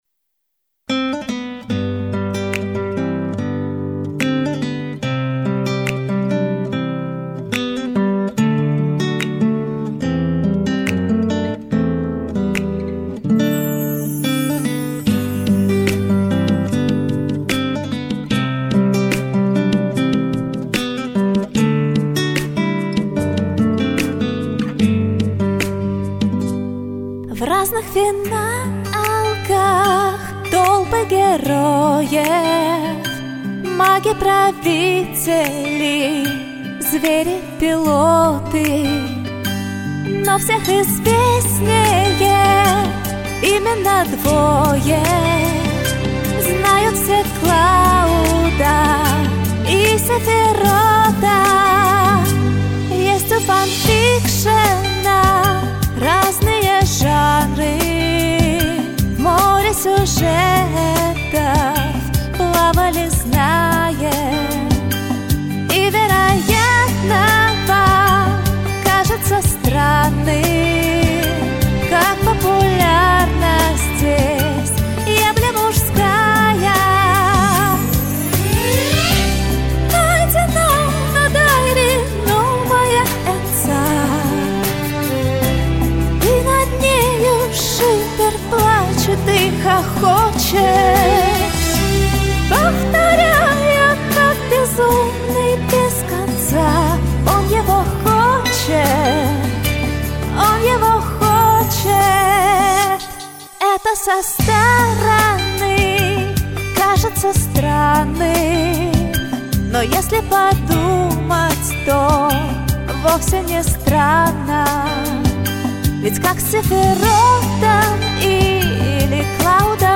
Форма: песня